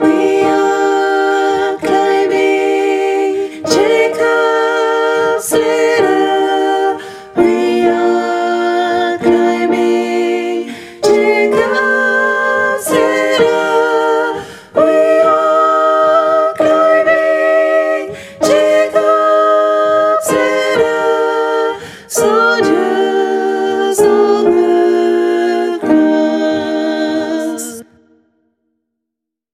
Soprano et autres voix en arrière-plan